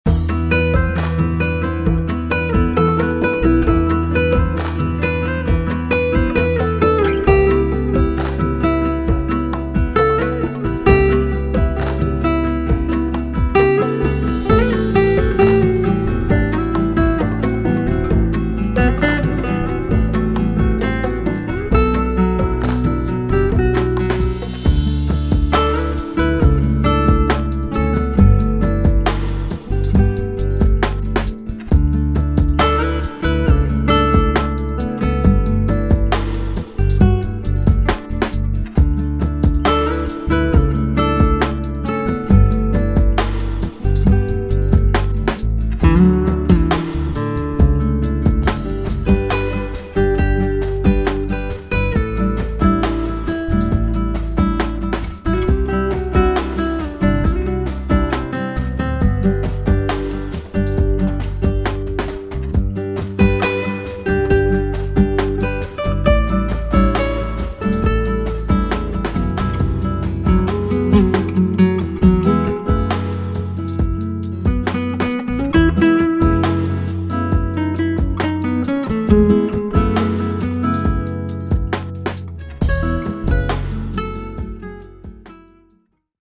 68 BPM – D – Sweet Like Honey – DRY
133 BPM – E – Sweet Vertigo – DRY
Music loops used in the demo are not included.